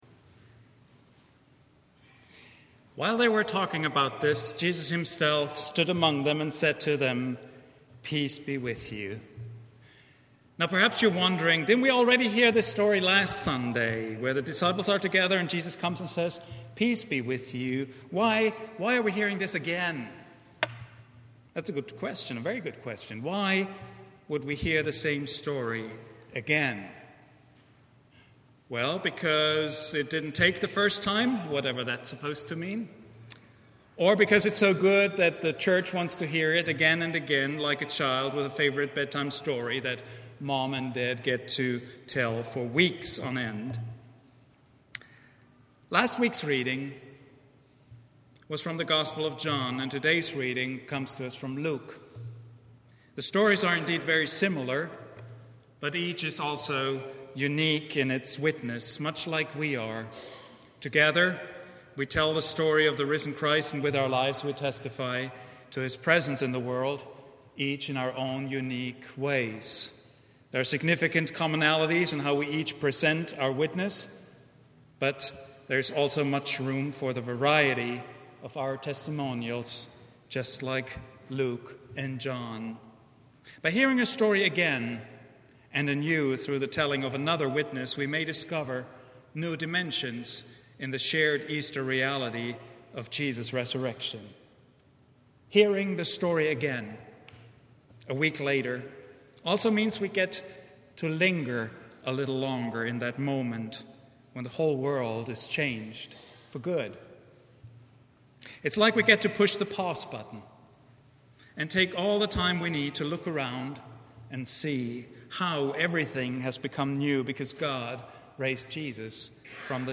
Opened Minds — Vine Street Christian Church